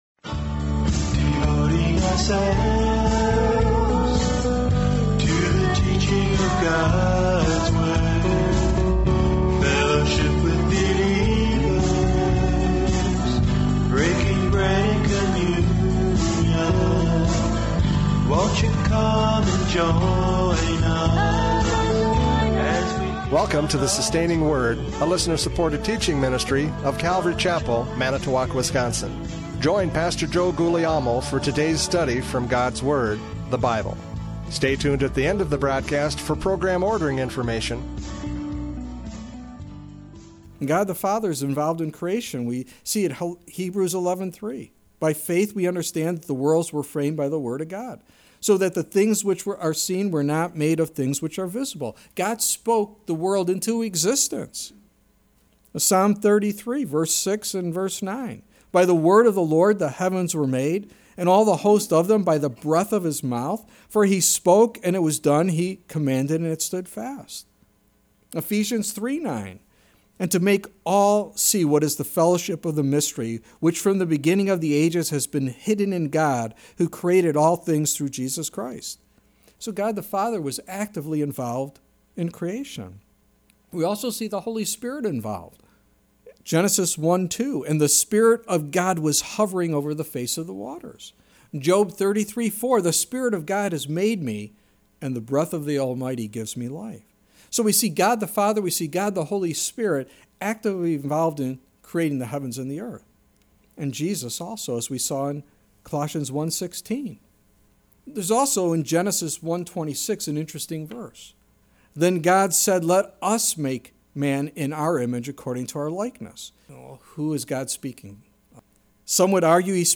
John 5:17-18 Service Type: Radio Programs « John 5:17-18 Equality in Creation!